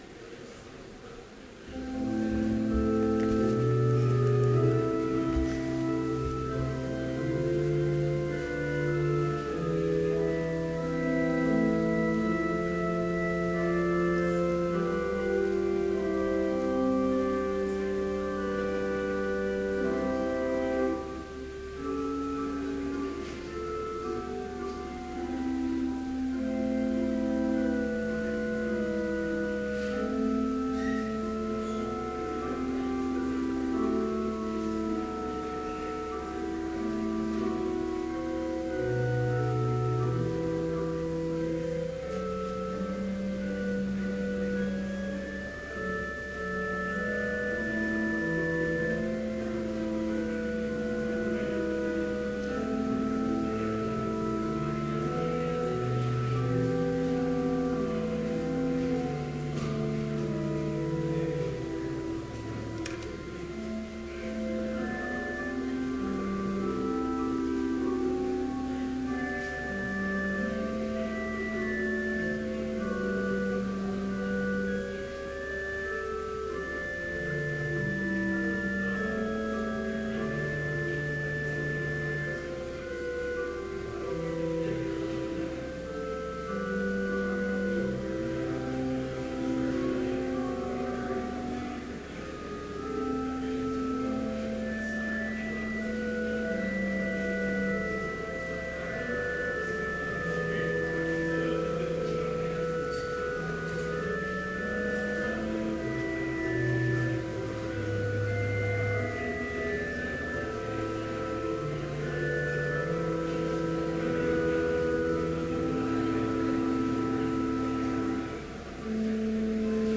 Complete service audio for Chapel - November 19, 2014
Prelude
Homily